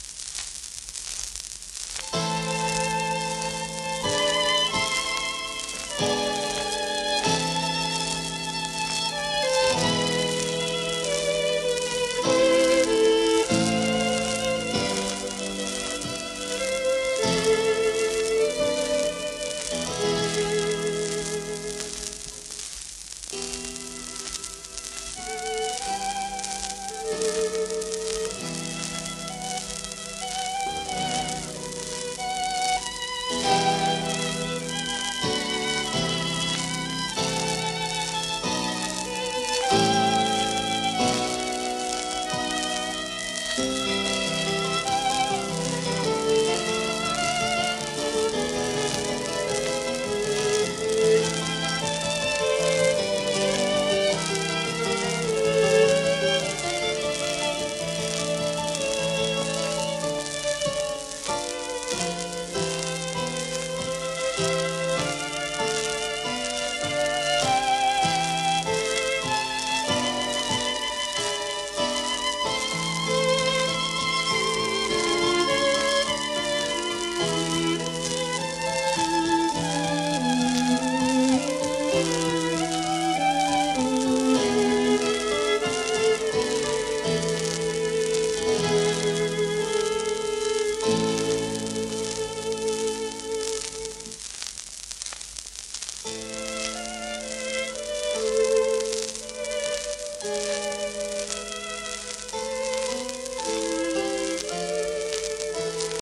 盤質A- *小キズ,軽度の盤反り
シェルマン アートワークスのSPレコード